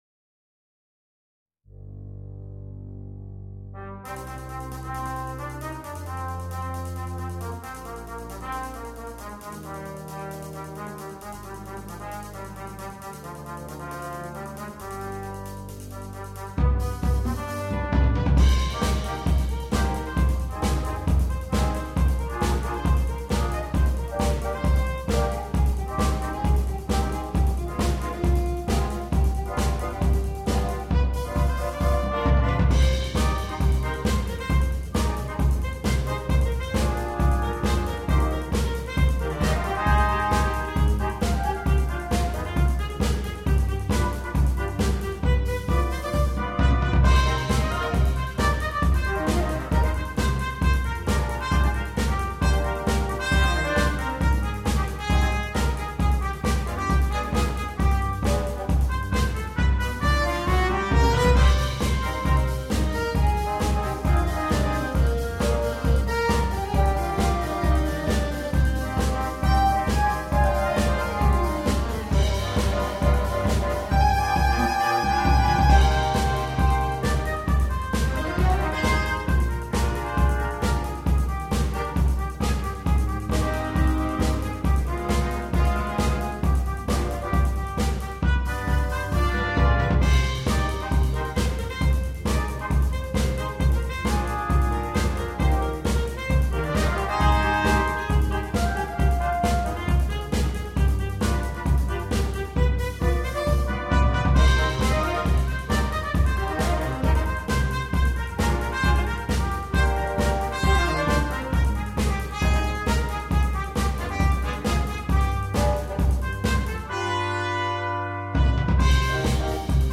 на смешанный состав